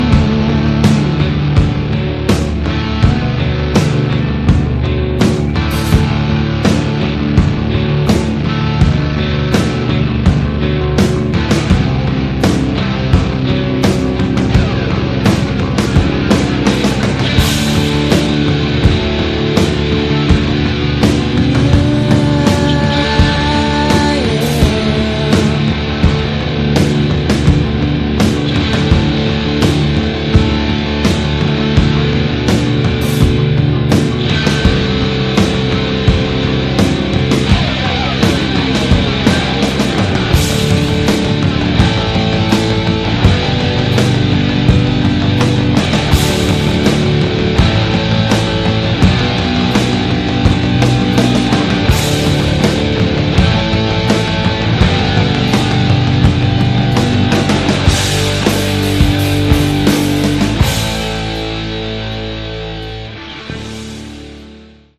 Category: Sleaze - Glam